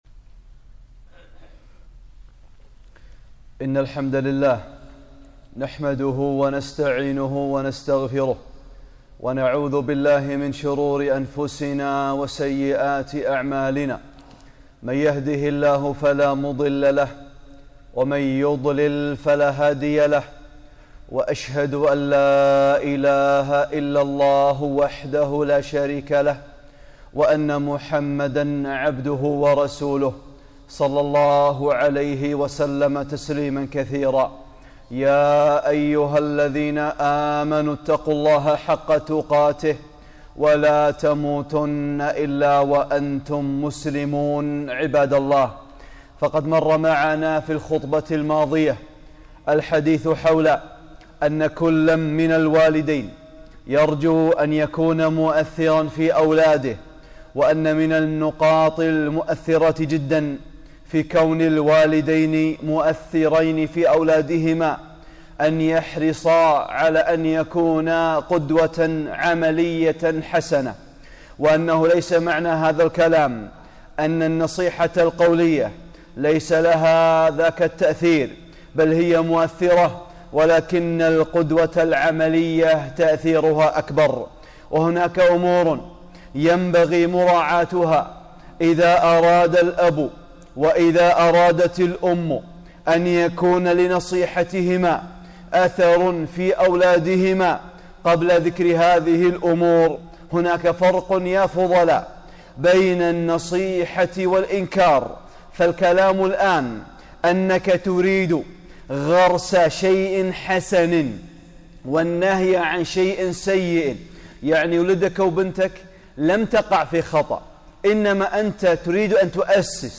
(18) خطبة - آداب النصيحة - أمور هامة متعلقة بالآباء والأمهات